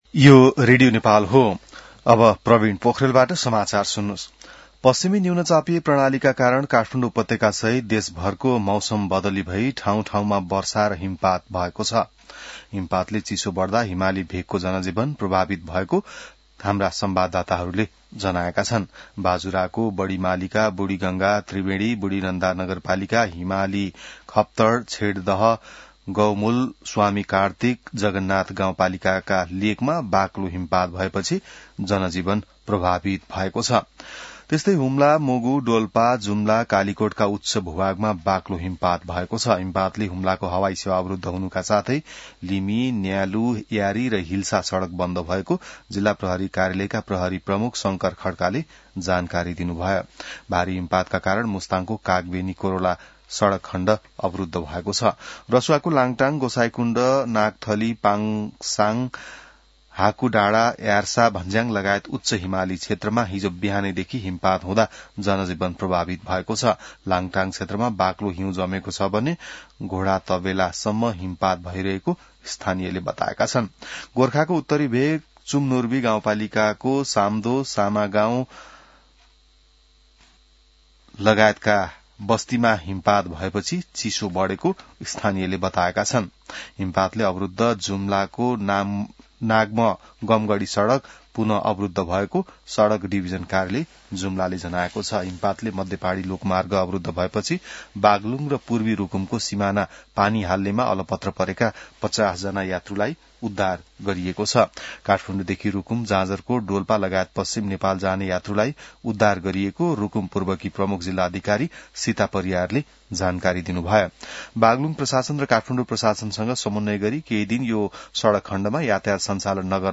बिहान ६ बजेको नेपाली समाचार : १५ माघ , २०८२